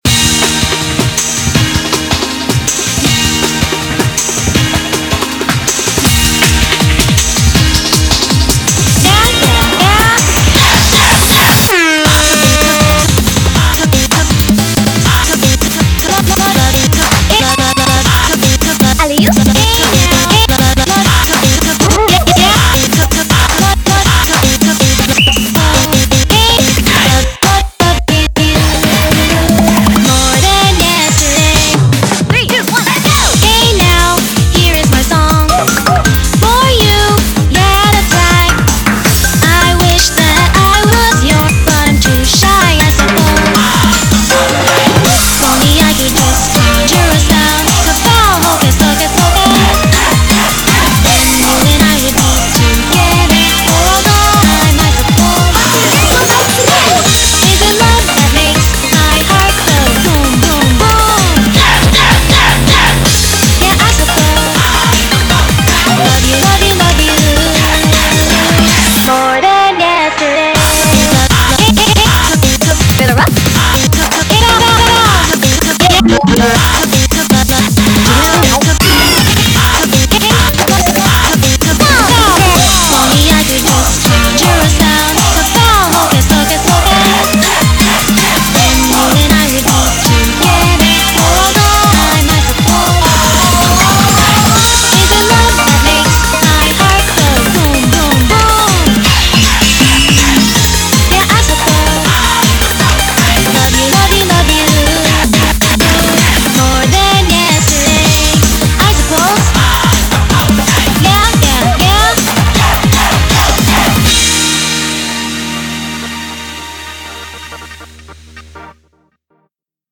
BPM80-320